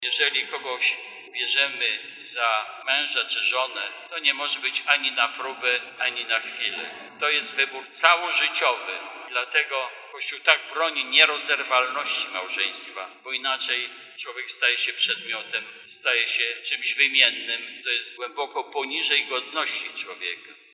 Bp senior diecezji warszawsko-praski przewodniczył mszy św. w parafii Najczystszego Serca Maryi na Placu Szembeka podczas dorocznego Diecezjalnego Dnia Wspólnoty Domowego Kościoła – gałęzie rodzinnej Ruchu Światło-Życie.